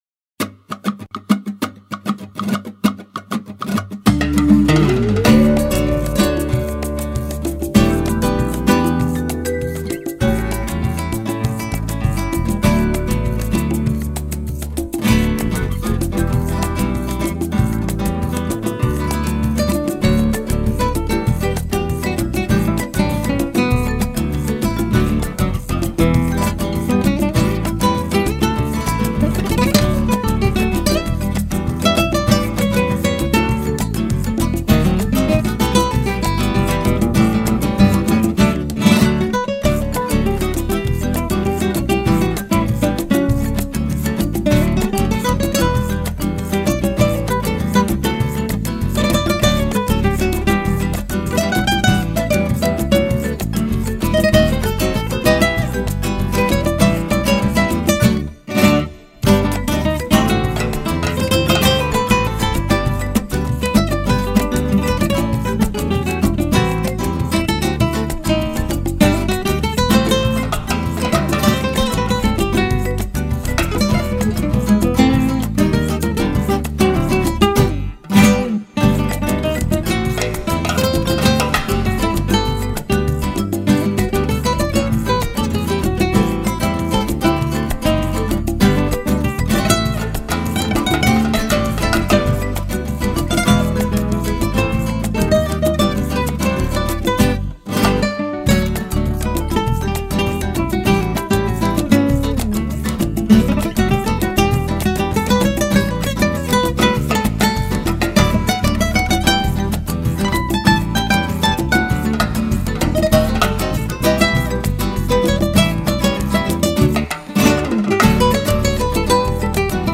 332   03:34:00   Faixa:     Instrumental
Versão RUMBA FLAMENCA